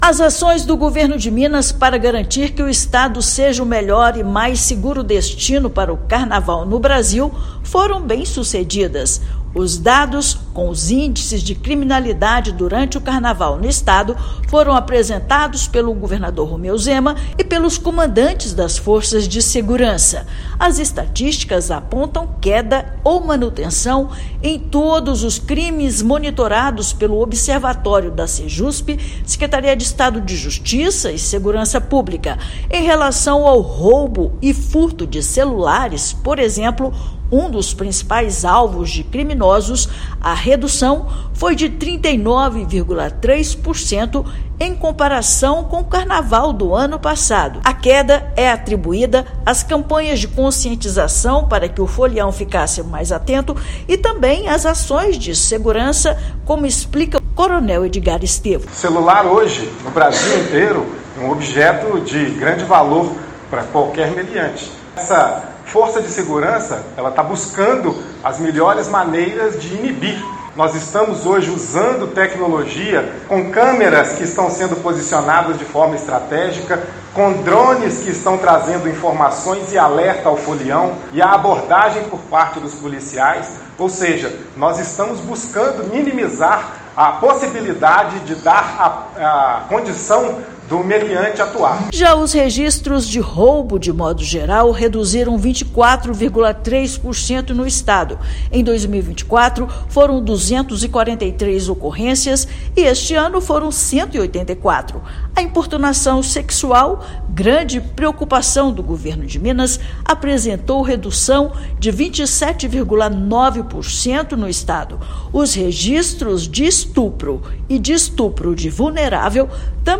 [RÁDIO] Governo de Minas registra redução em crimes monitorados durante o Carnaval 2025, como homicídios, roubos e importunação sexual
Índices de estupros em Belo Horizonte tiveram a queda mais significativa, de 80%. Número de celulares roubados no Estado recuou mais de 39%; integração da segurança pública, tecnologia, campanhas de conscientização e reforço no efetivo transformam Minas em um destino seguro. Ouça matéria de rádio.